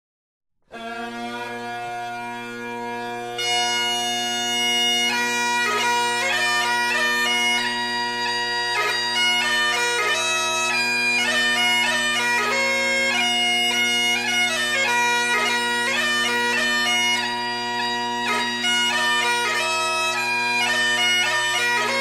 на волынке в кельтском стиле